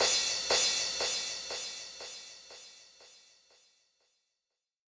ITA Crash FX D.wav